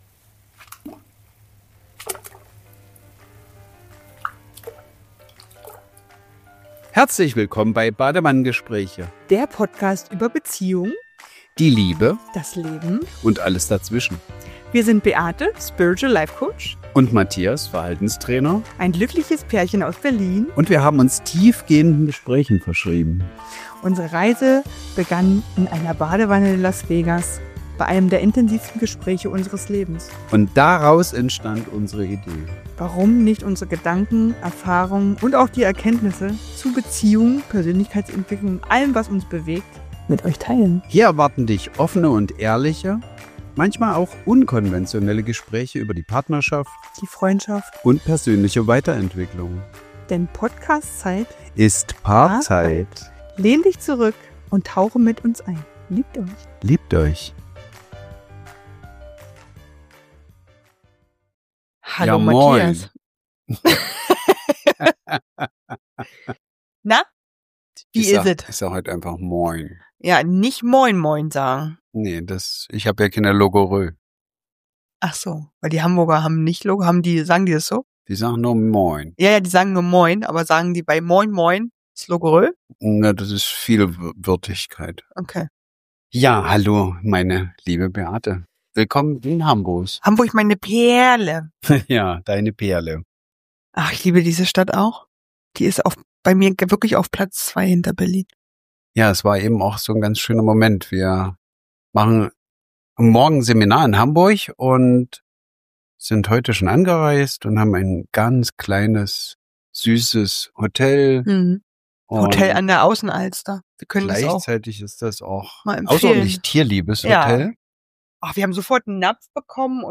Zwischendurch hört ihr Luna auch mal im Hintergrund – das ist unser ganz realer Badewannen-Vibe: warm, nah und absolut unperfekt perfekt.